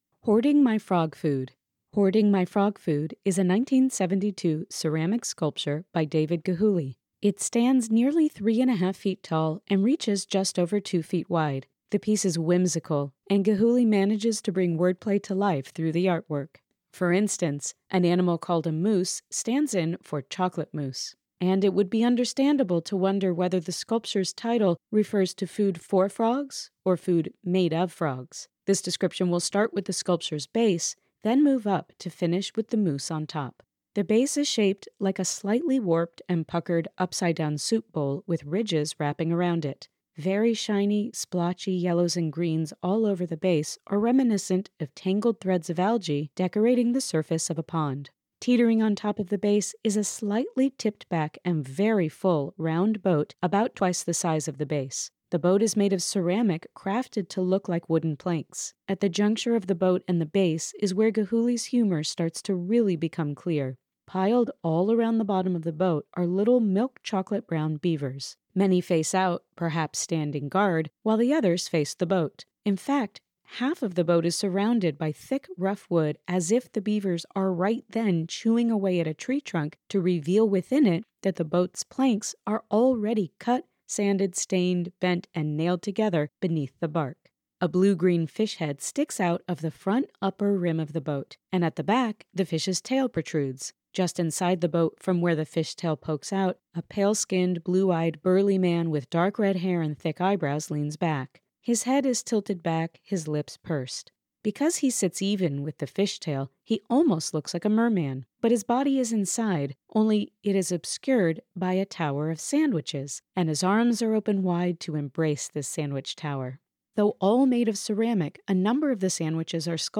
Audio Description (03:20)